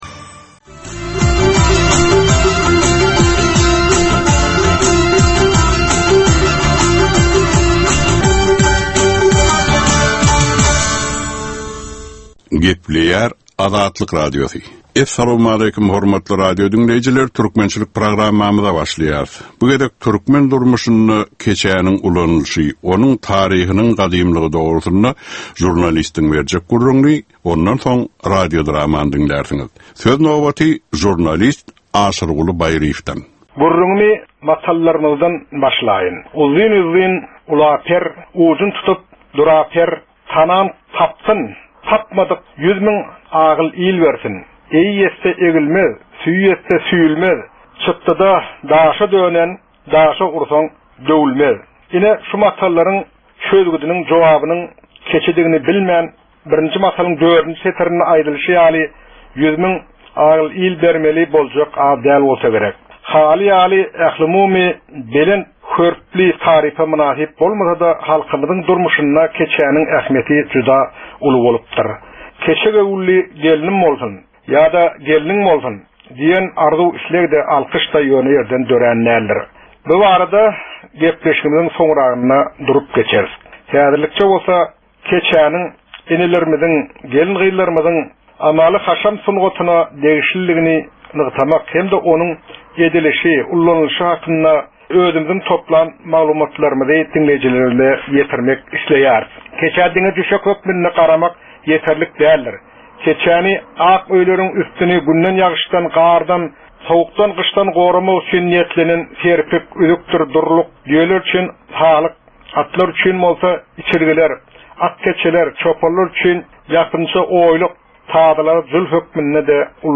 Türkmen halkynyň däp-dessurlary we olaryň dürli meseleleri barada 10 minutlyk ýörite gepleşik. Bu programmanyň dowamynda türkmen jemgyýetiniň şu günki meseleleri barada taýýarlanylan radio-dramalar hem efire berilýär.